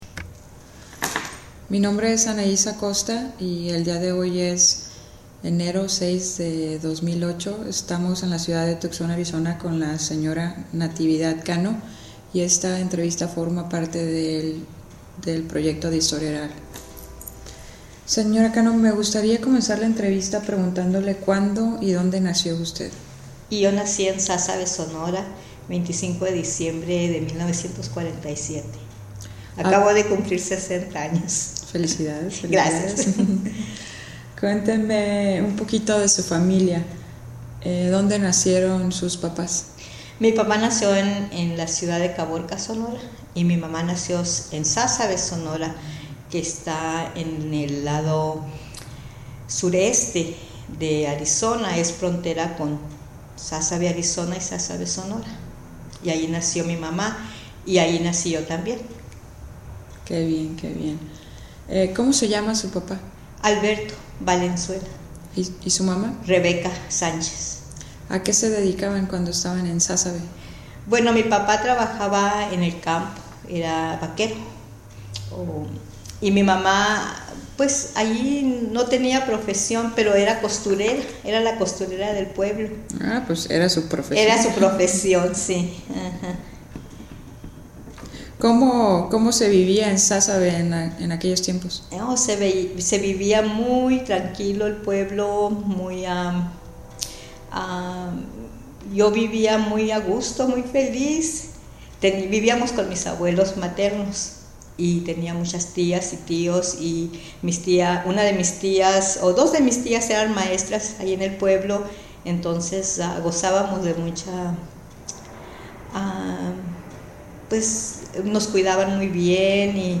Location Tucson, Arizona